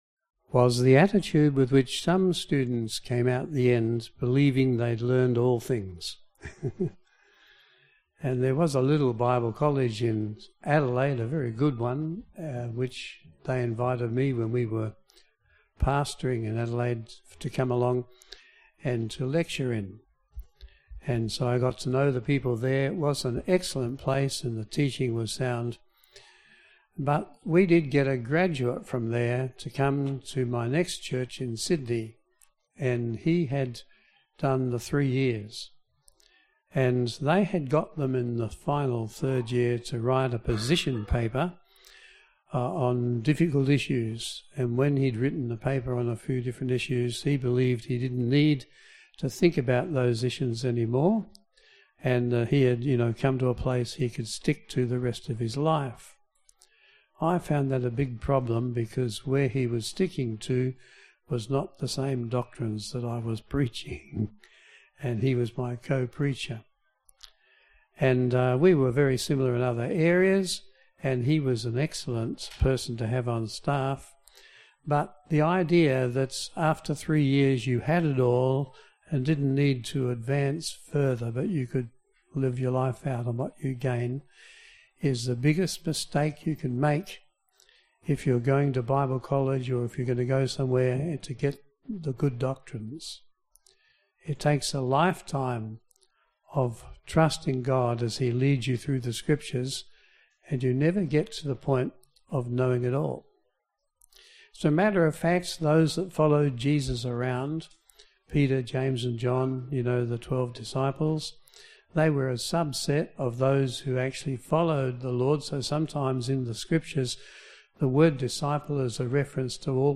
Service Type: AM Service